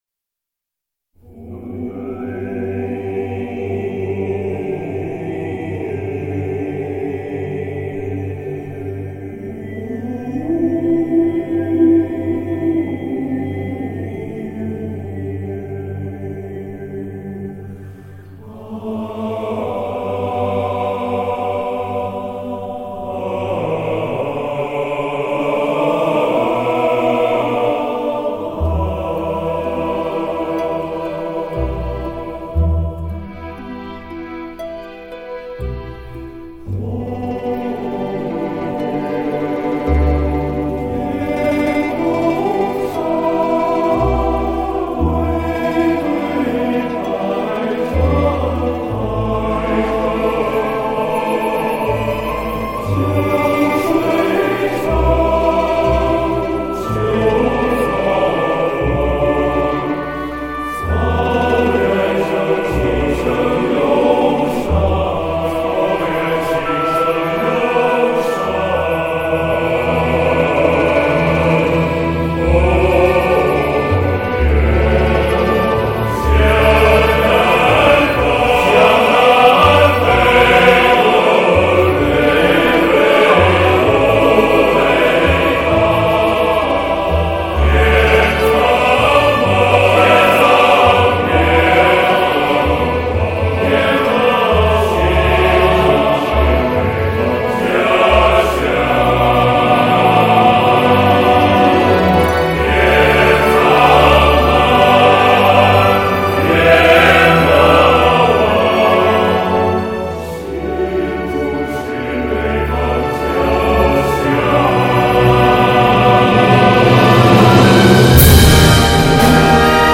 前所未有的中国全男声合唱大碟 激情澎湃，气势磅礴无与伦比！ 现场再现，声声浪潮铺天盖地！